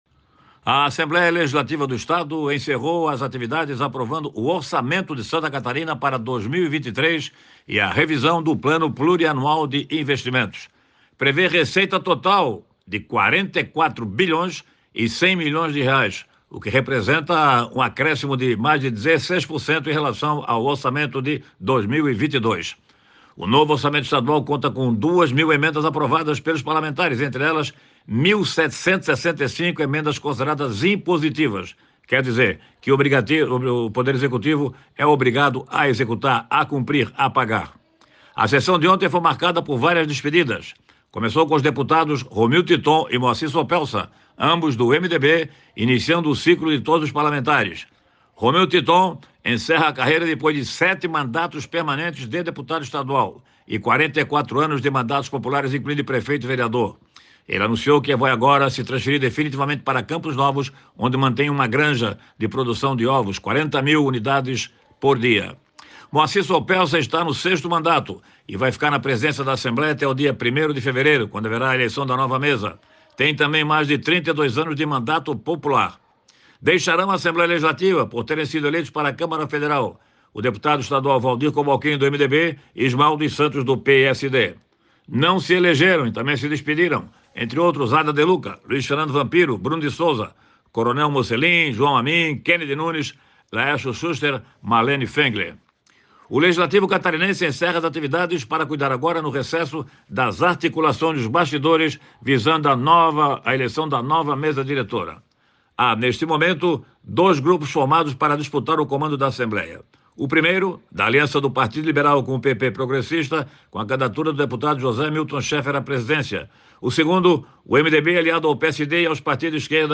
Jornalista comenta que o novo orçamento para o ano que vem conta com duas mil emendas aprovadas pelos parlamentares catarinenses e um acréscimo de 17% em relação ao orçamento de 2022